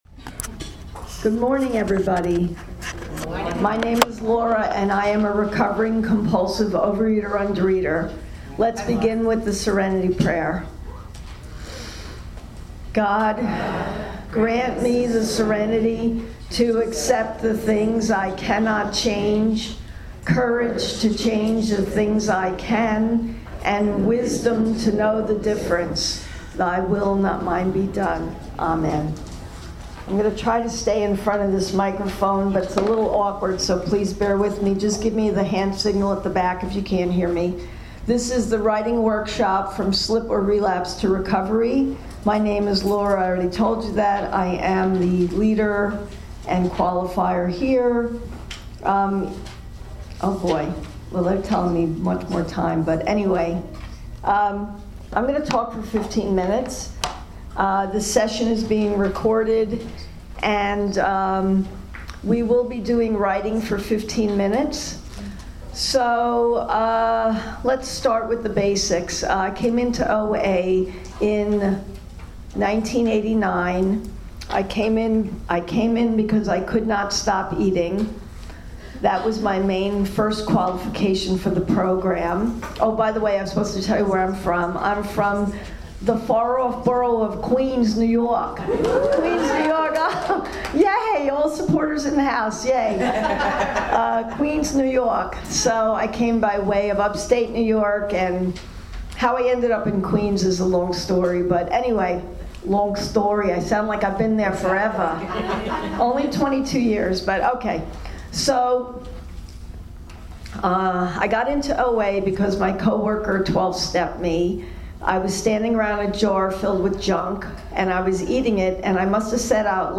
This workshop was held at the 2024 OA Region 6 convention, in October in Nashua, NH, US.